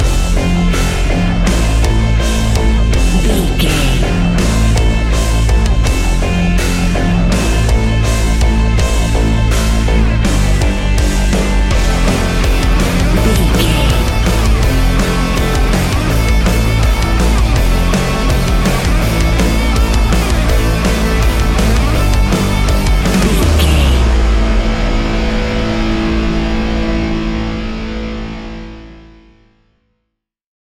Ionian/Major
A♭
hard rock
heavy rock
guitars
heavy metal
instrumentals